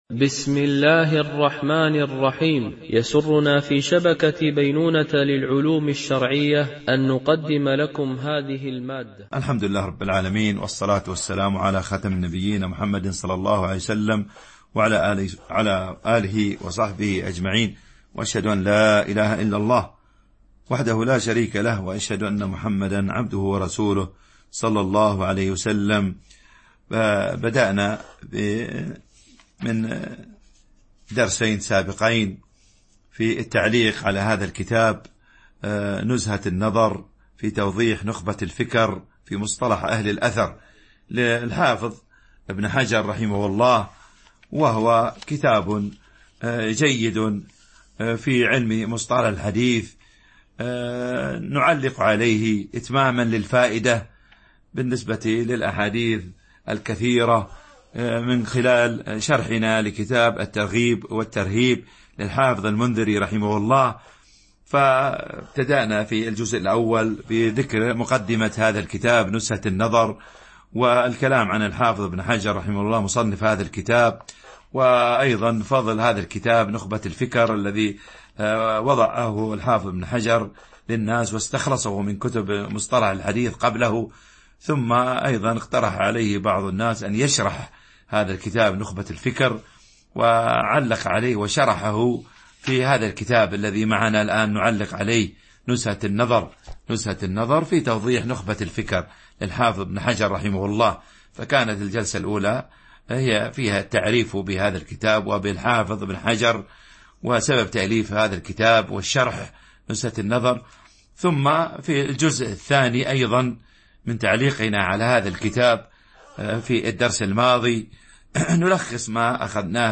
شرح نزهة النظر في توضيح نخبة الفكر - الدرس 3